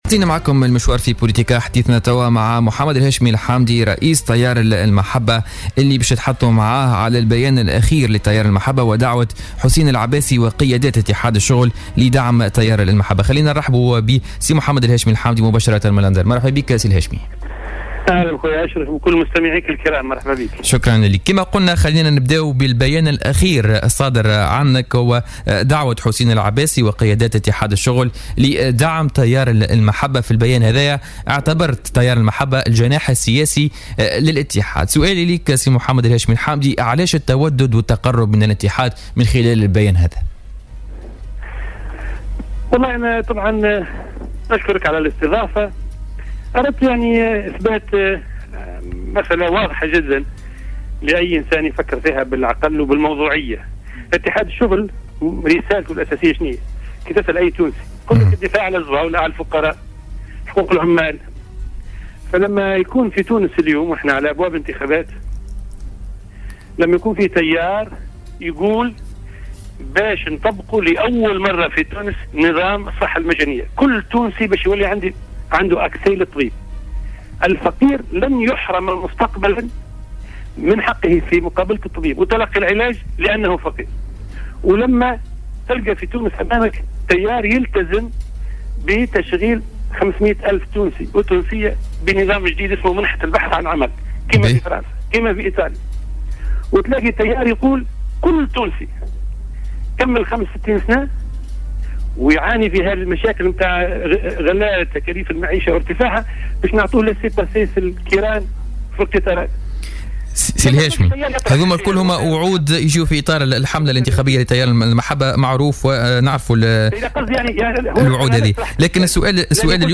Le leader et fondateur du parti le Courant Al Mahaba, Hechmi Hamdi a déclaré sur les ondes de Jawhara Fm que son récent appel lancé à l’UGTT pour soutenir son parti dans les futures élections législatives et présidentielle s’inscrit dans le cadre du rapprochement des points de vue pour un seul et même dessein commun, celui de défendre les classes les plus pauvres.